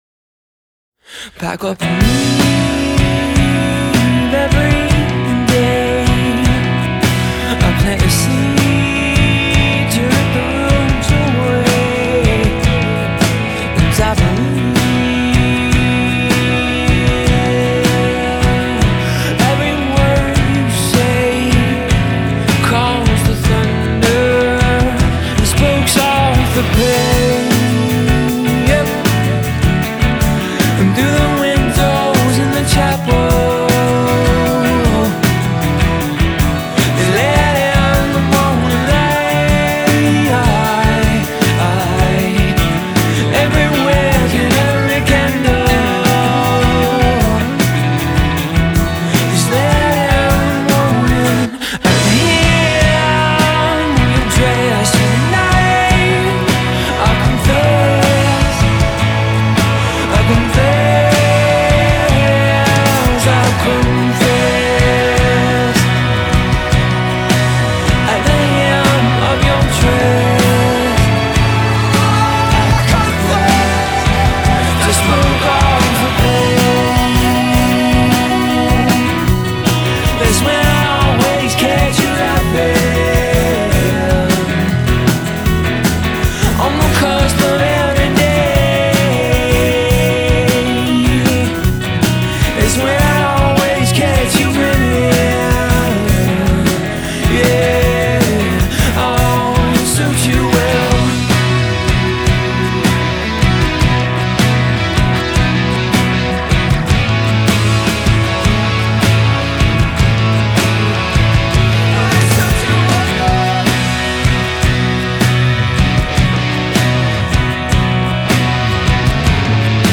melodic pop band